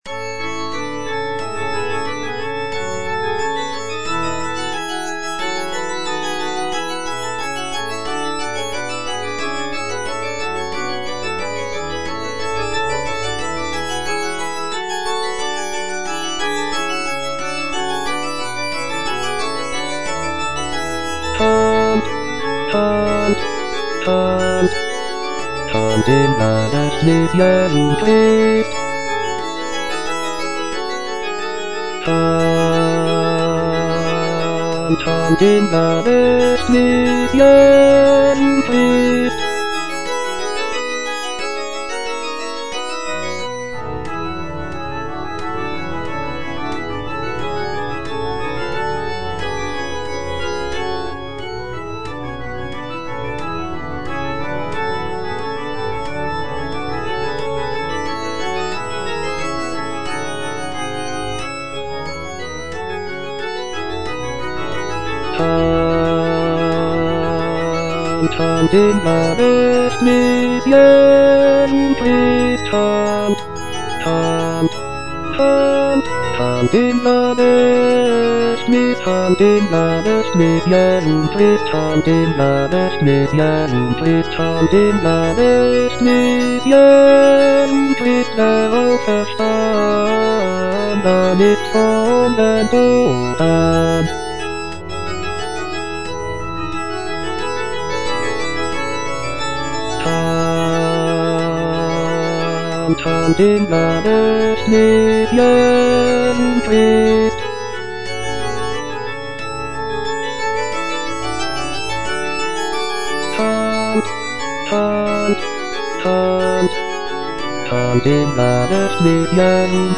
Bass (Voice with metronome) Ads stop